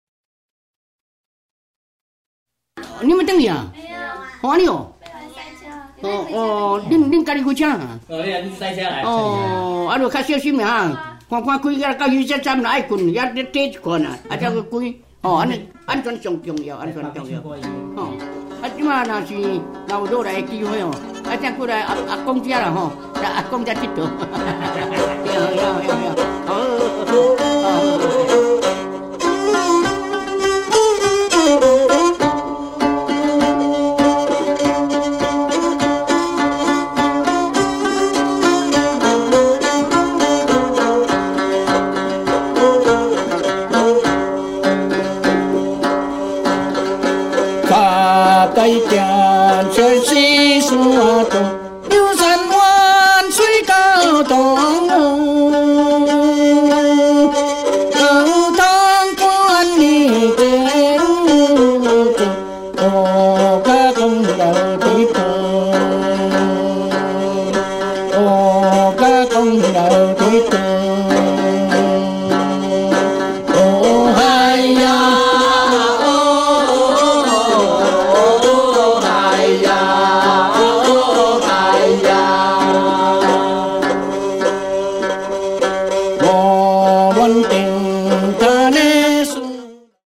恆春民謠彈唱
兩條線的月琴、椰子變的殼仔弦、林投樹做的大管弦